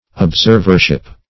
Observership \Ob*serv"er*ship\, n.
observership.mp3